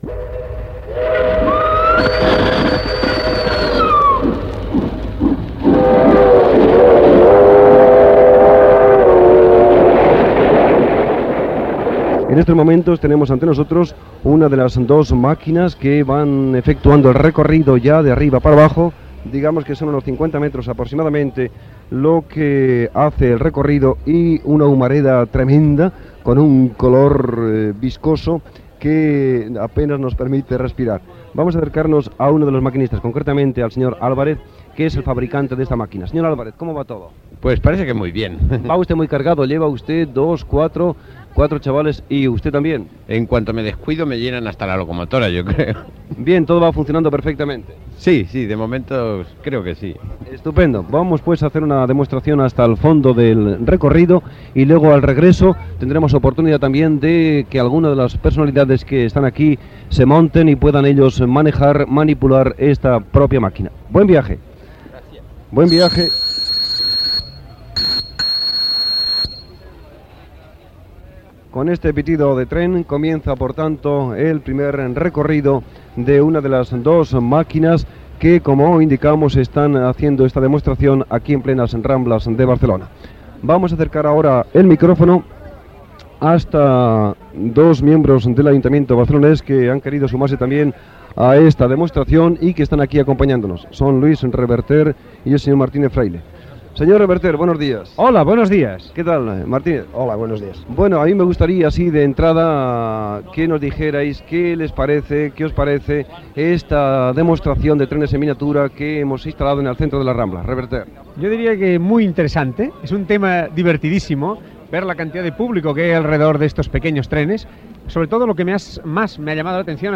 Recorregut de trens en miniatura a la Rambla de Barcelona. Entrevistes a un maquinista i als regidors de l'Ajuntament de Barcelona Lluís Reverter i Martínez Fraile.
Entreteniment